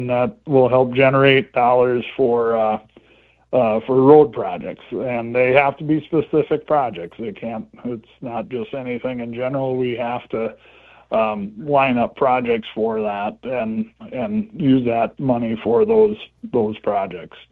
Commissioner Steve Schmitt: